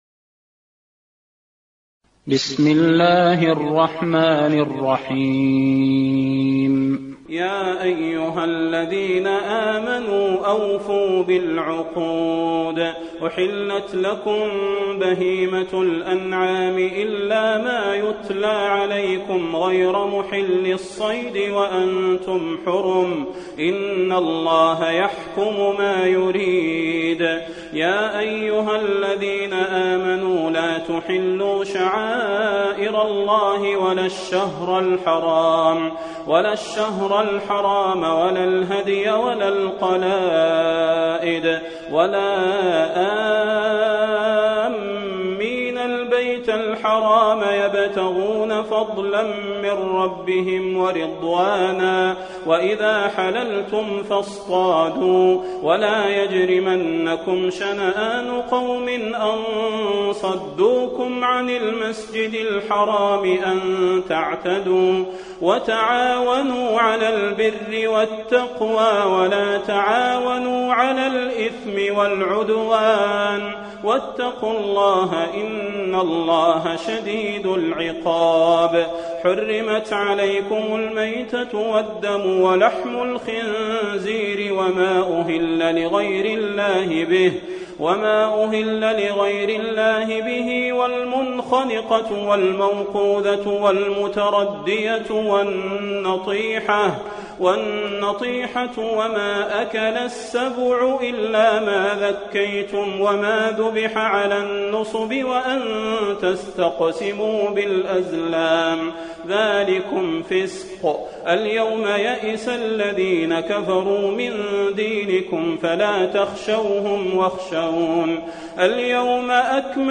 المكان: المسجد النبوي المائدة